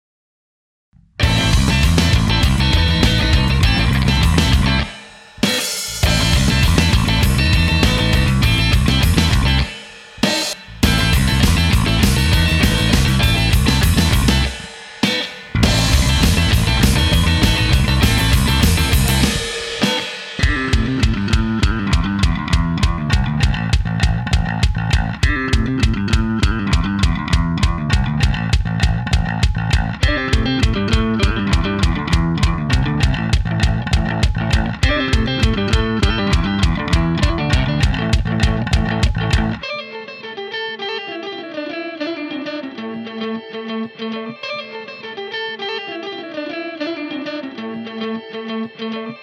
Ska Track
This is a ska track I wrote about 2 years ago, enjoy!